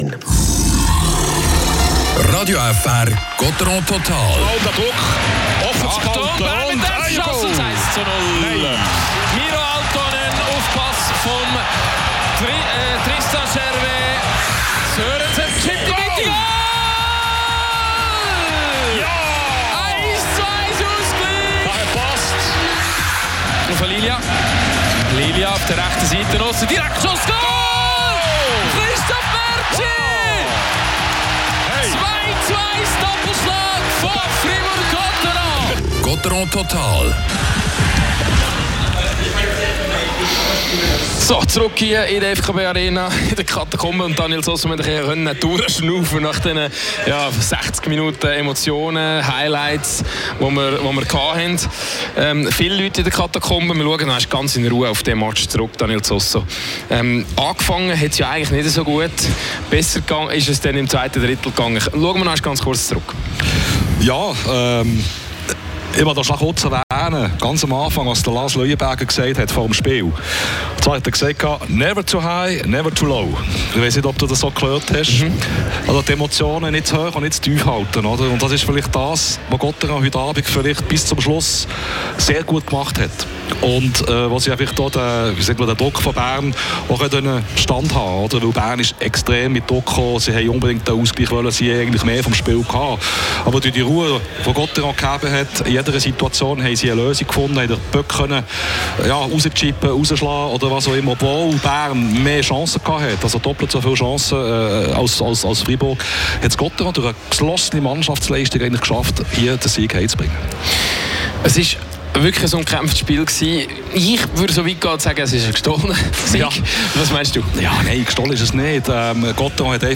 Gottéron gewinnt zu Hause gegen den SCB mit 2:1 und stockt somit in der Serie auf ein 3:1 auf. Die Expertise aus dem Stadion
Interview